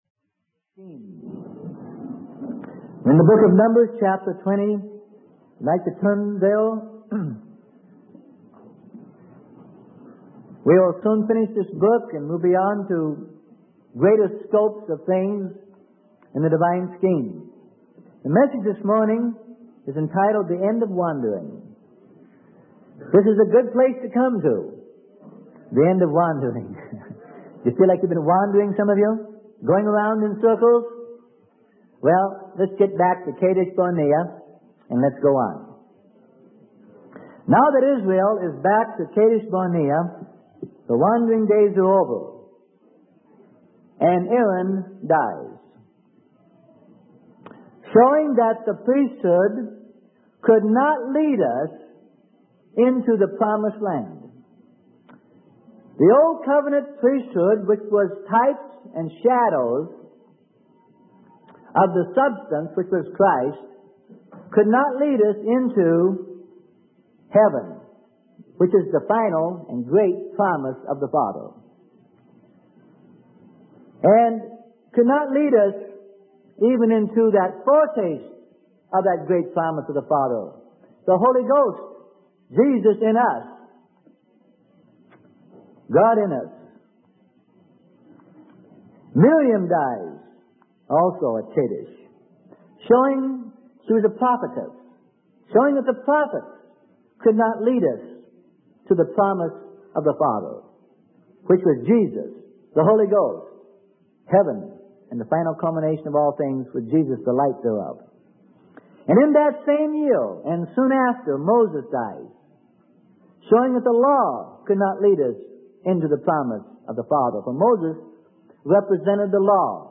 Sermon: God's Divine Scheme - Part 12 - Freely Given Online Library